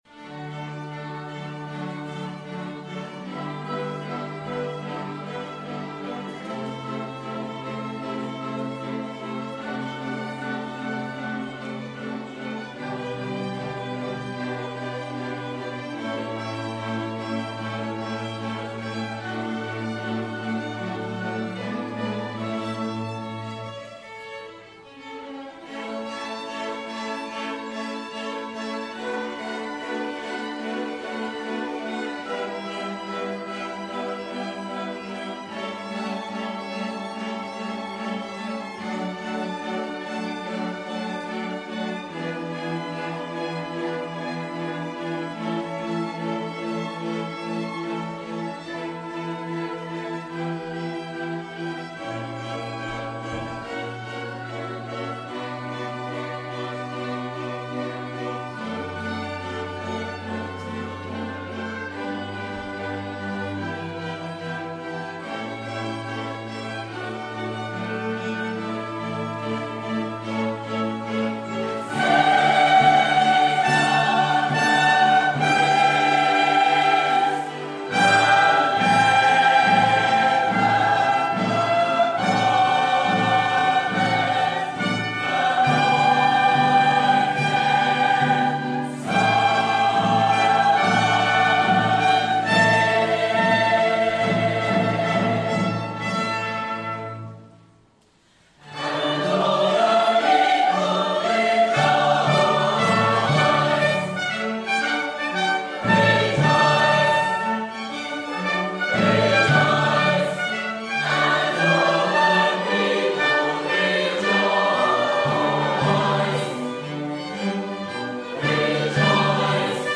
Performed by Take Note and the Orchestra